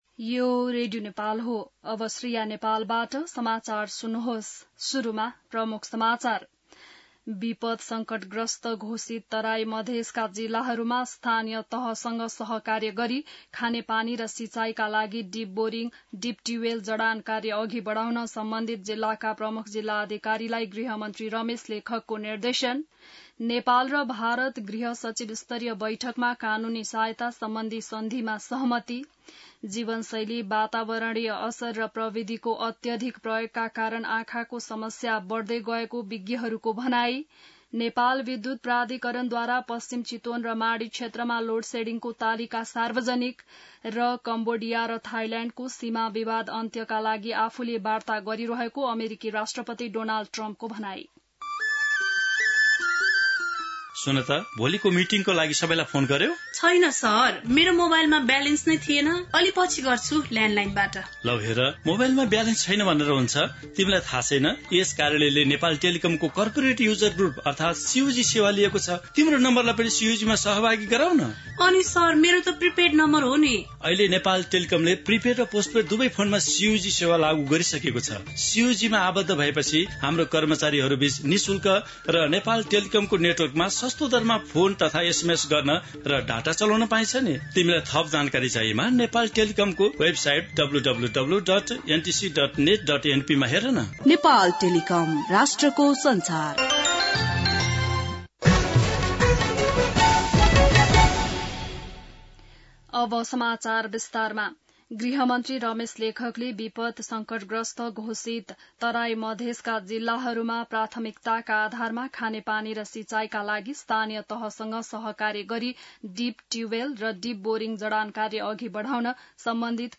बिहान ७ बजेको नेपाली समाचार : ११ साउन , २०८२